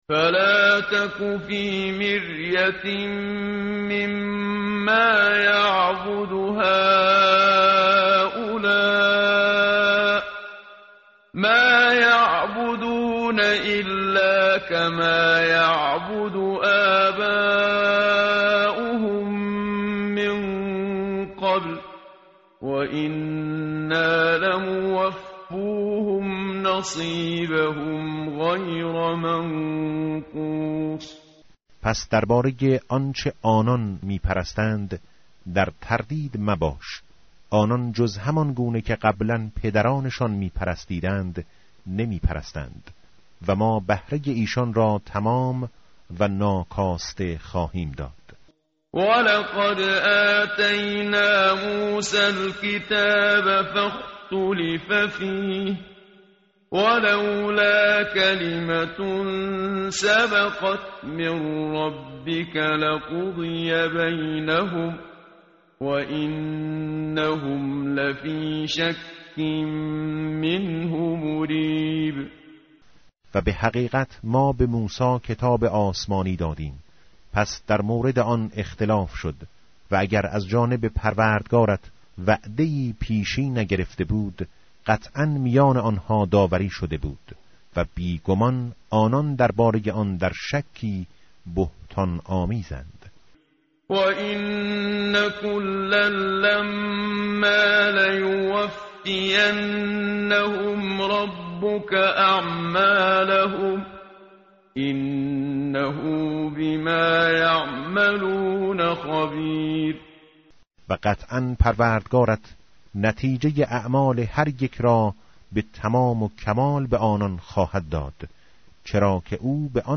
tartil_menshavi va tarjome_Page_234.mp3